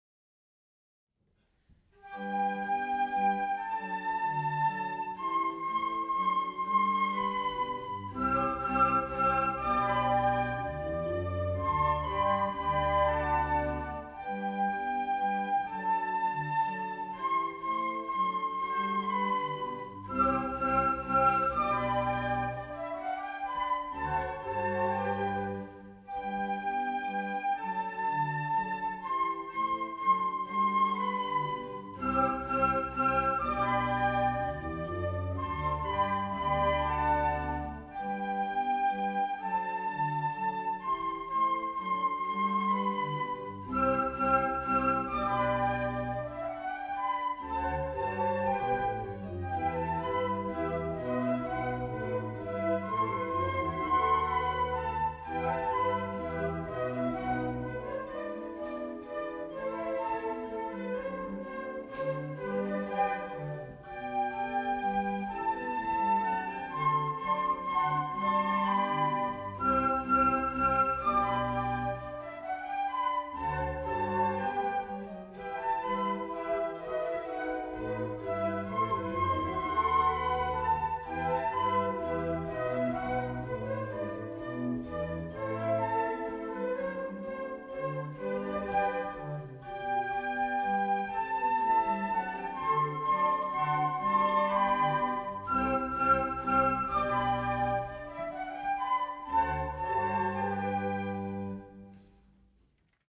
Querflöten Konzert
Orgel Leeste
Rund vierzig(!) Ausführende waren mit ihrer Querflöte
Ausschnitte aus dem Konzert: